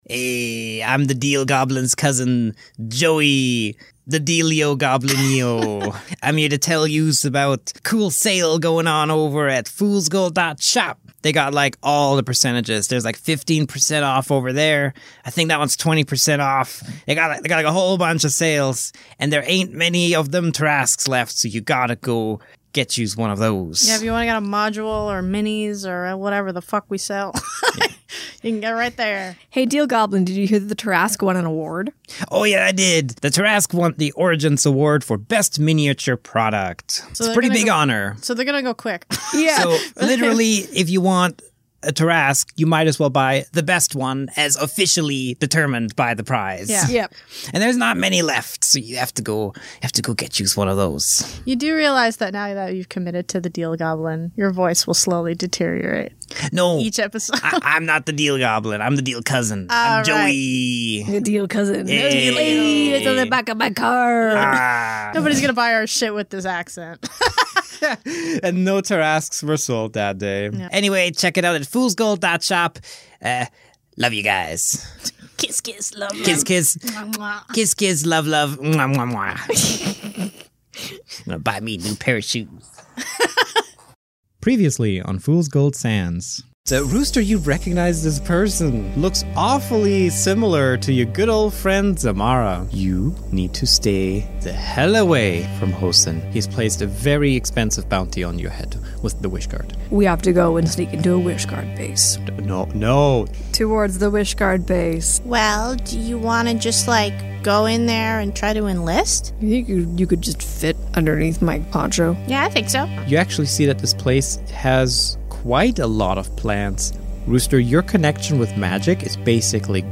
Library of Audio Fiction Podcasts